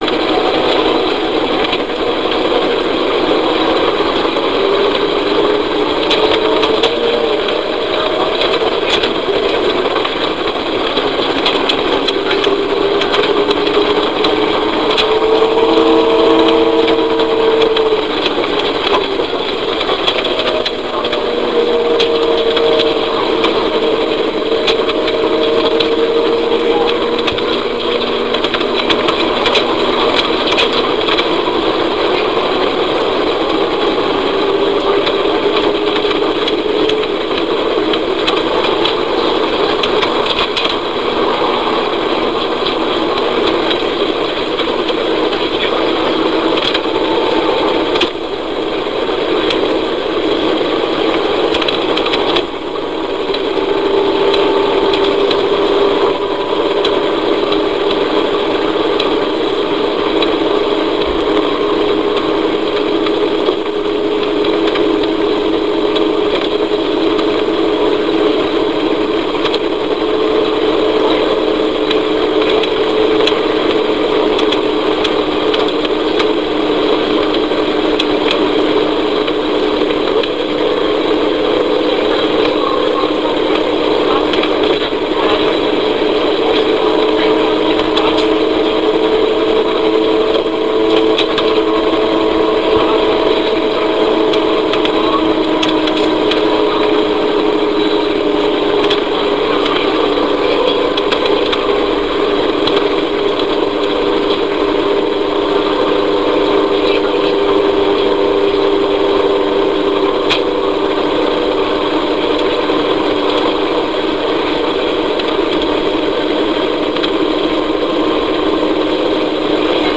Volvo Super Olympian 12m on rt. 118